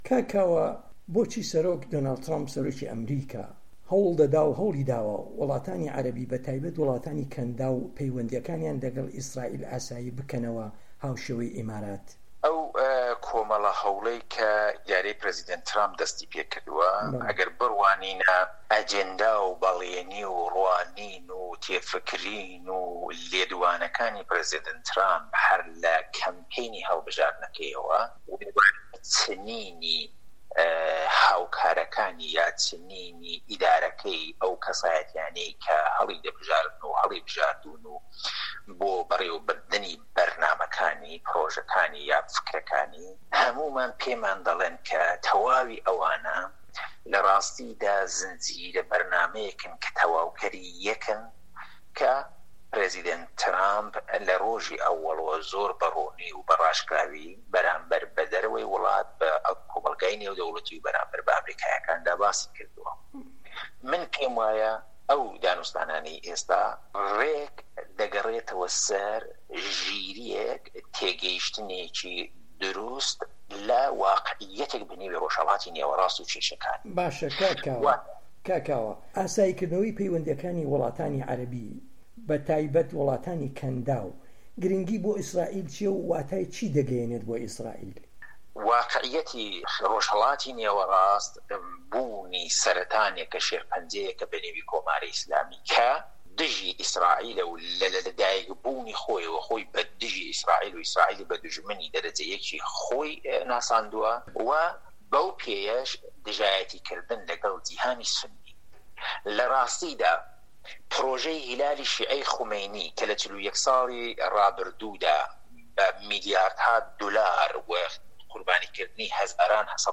ڕۆژهه‌ڵاتی ناوه‌ڕاست - گفتوگۆکان